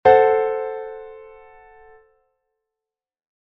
acorde2.mp3